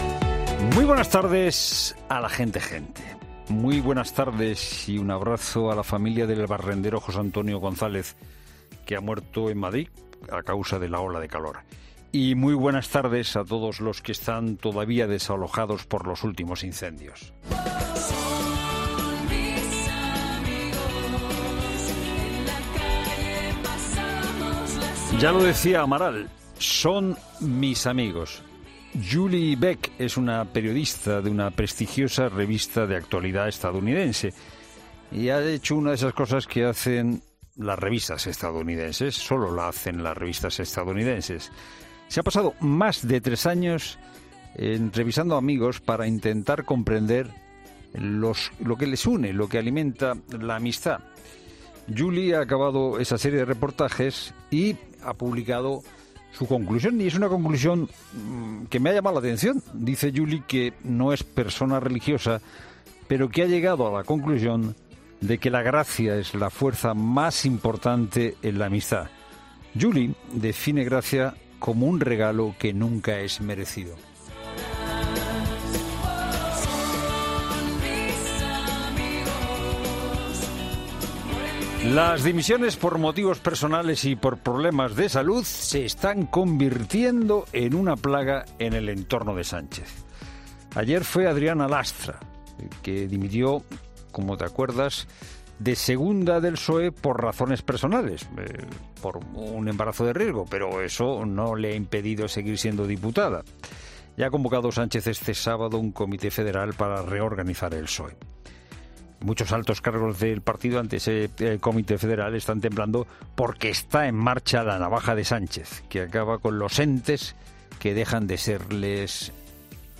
Monólogo de Fernando de Haro
El copresentador de 'La Tarde', Fernando de Haro, reflexiona sobre las principales noticias de este martes